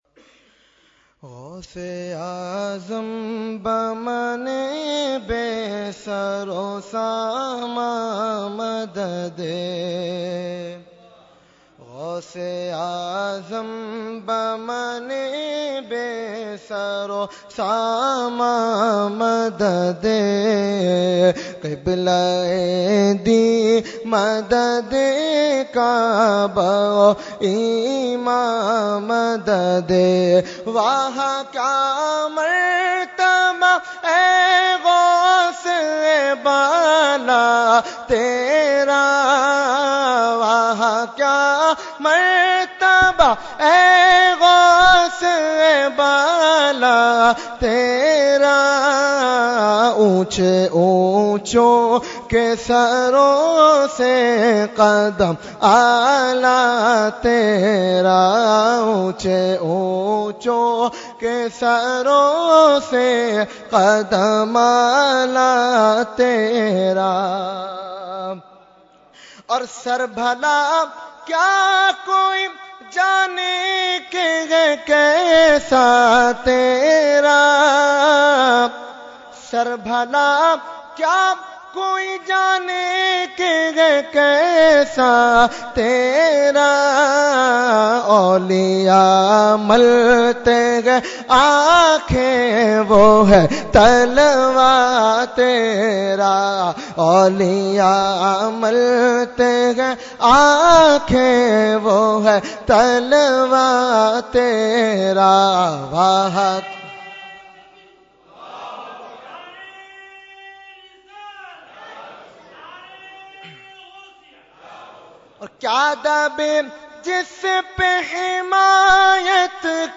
Category : Manqabat | Language : UrduEvent : 11veen Shareef 2017